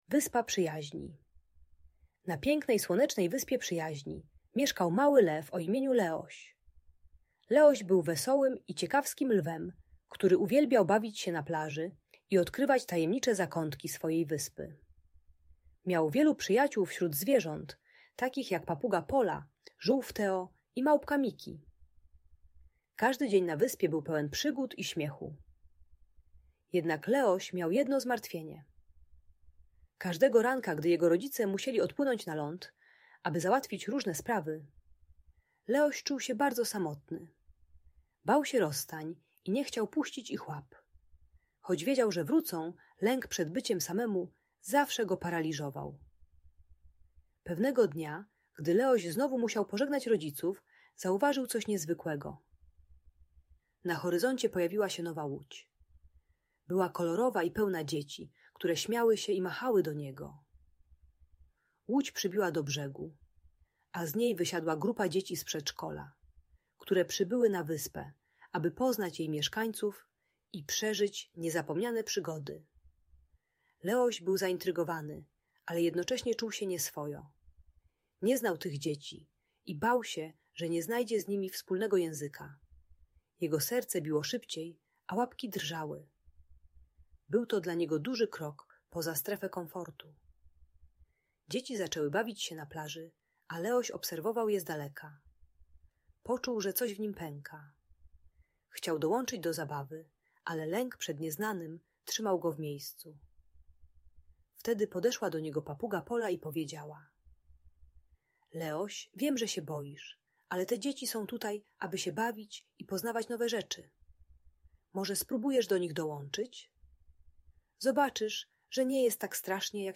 Wyspa Przyjaźni: Historia Leosia - Audiobajka